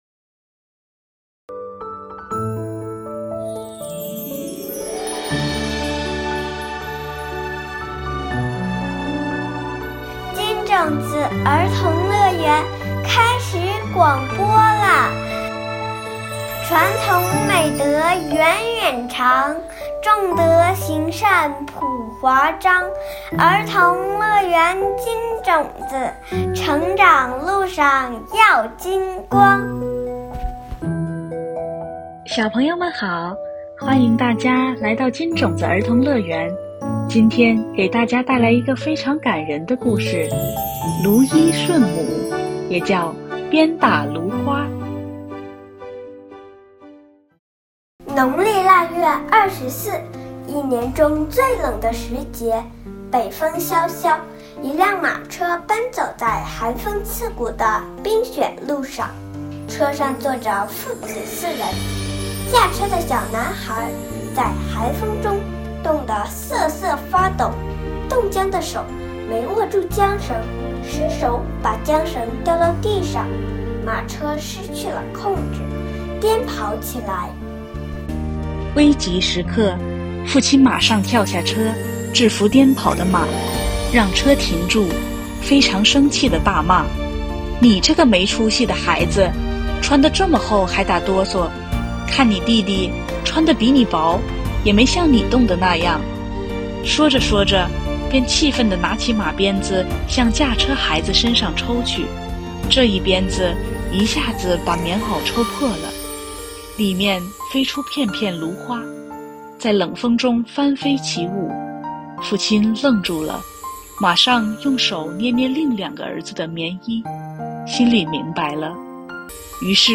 金種子兒童樂園系列廣播故事（音頻）： 第三期《蘆衣順母》